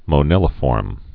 (mō-nĭlə-fôrm)